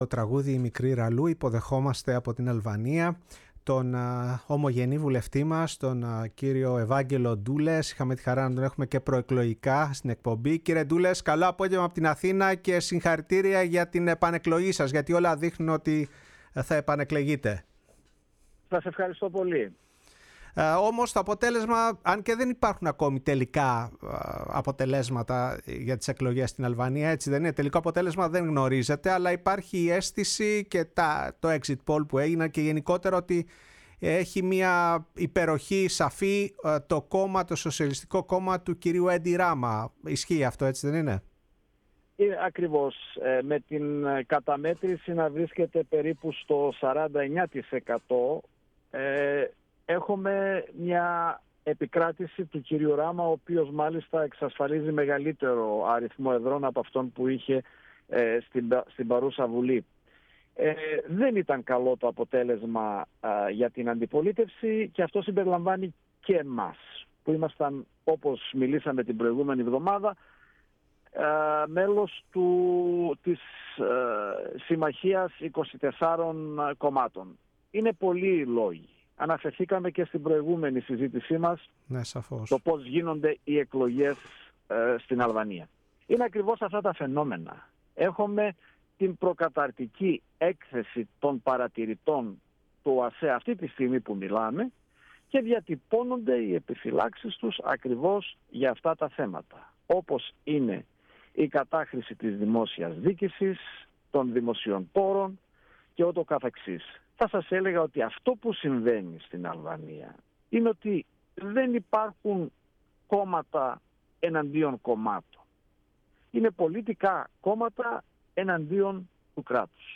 Η ΦΩΝΗ ΤΗΣ ΕΛΛΑΔΑΣ Η Παγκοσμια Φωνη μας ΣΥΝΕΝΤΕΥΞΕΙΣ Συνεντεύξεις Αλβανια εκλογες ΕΥΑΓΓΕΛΟΣ ΝΤΟΥΛΕΣ ομογενεια ΧΕΙΜΑΡΡΑ